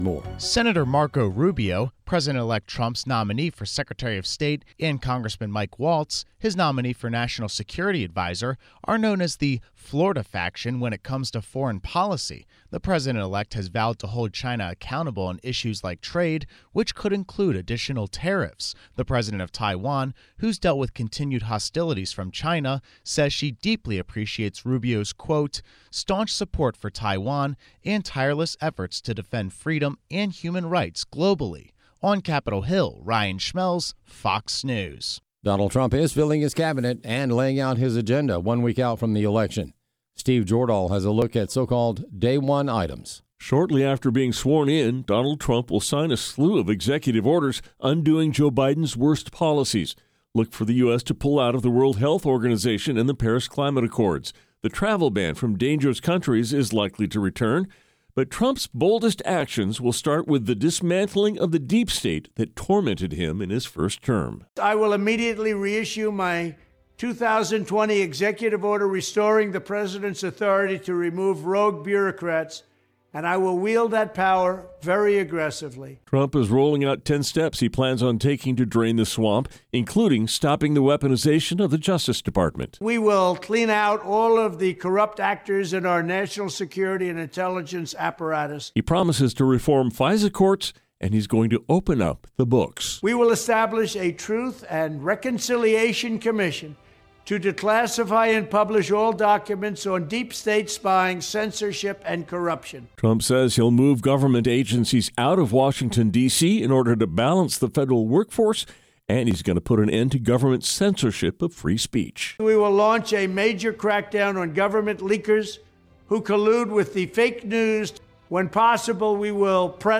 Callers share their thoughts about the coming administration